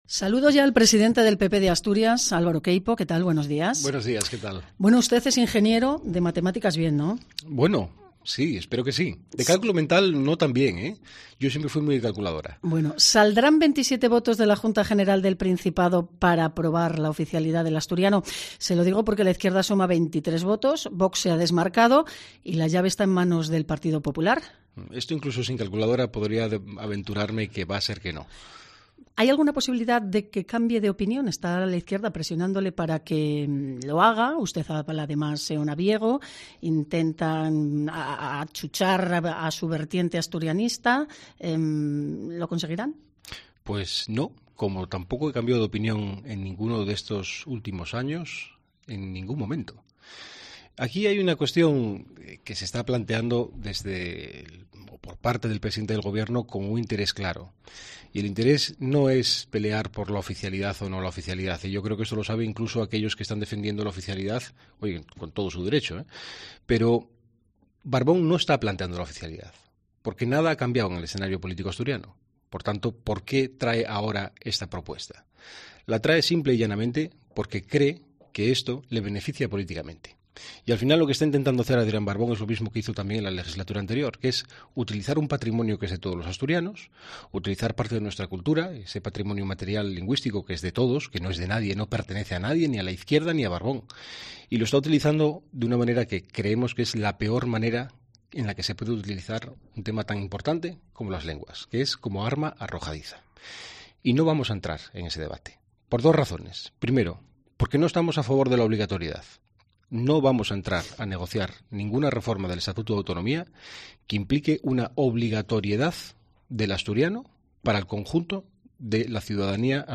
Entrevista a Álvaro Queipo, presidente del PP de Asturias